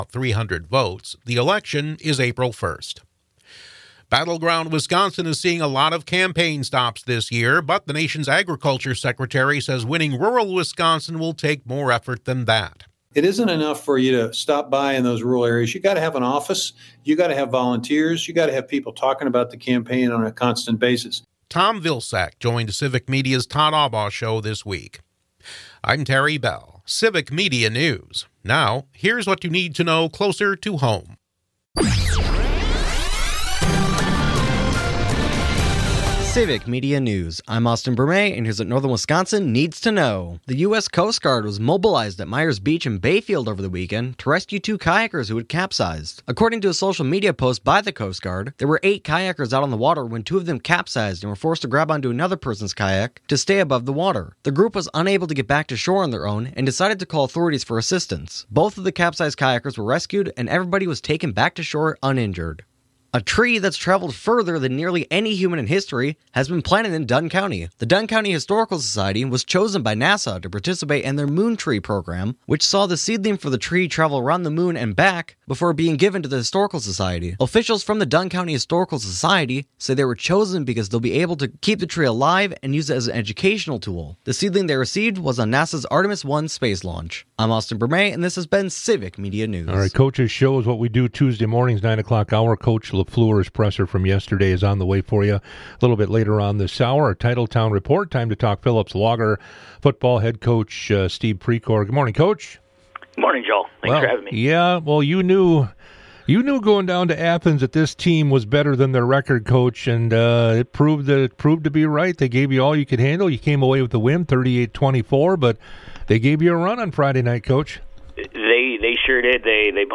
Interviews and special broadcasts from 98Q Country in Park Falls.